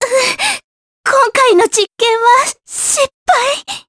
voices / heroes / jp
Mediana-Vox_Dead_jp.wav